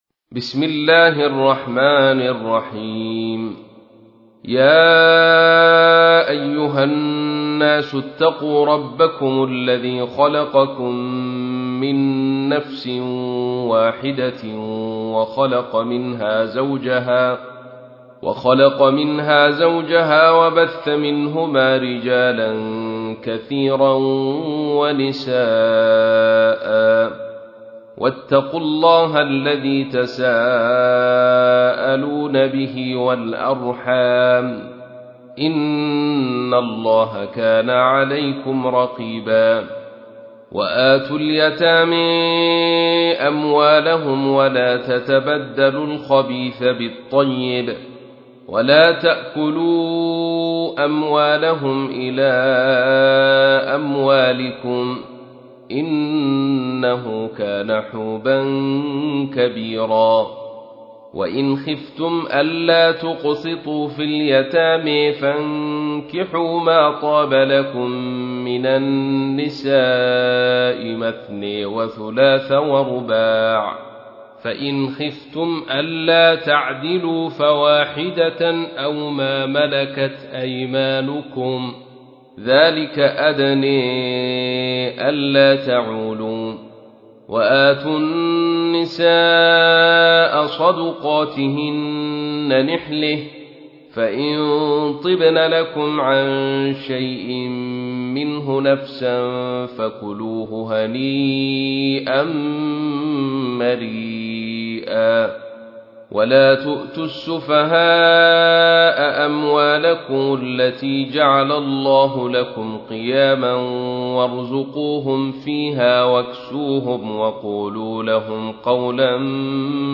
تحميل : 4. سورة النساء / القارئ عبد الرشيد صوفي / القرآن الكريم / موقع يا حسين